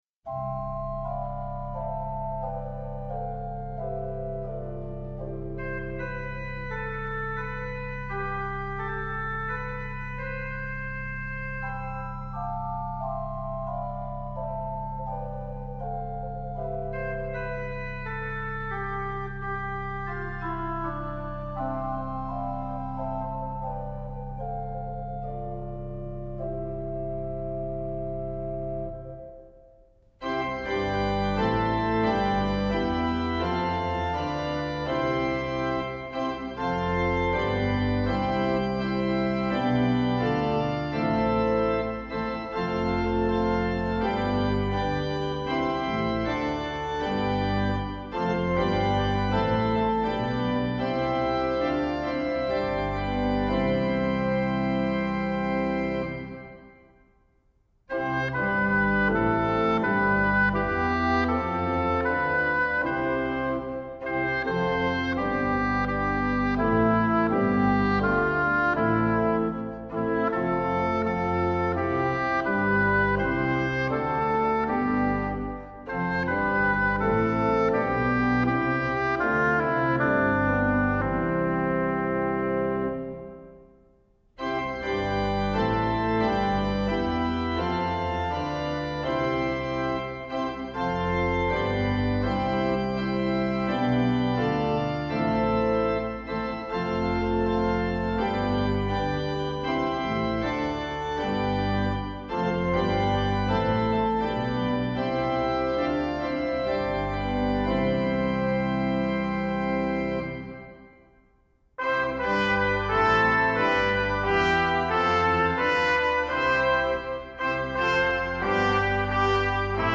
Hier sind die Orgelbegleitungen für die Choräle zum Mitsingen: